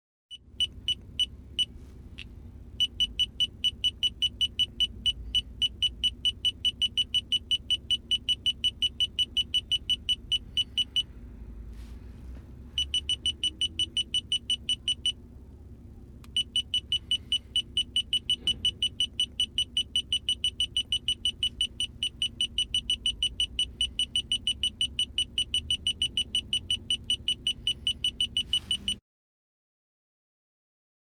transport
Radar Detector Automobile Beeps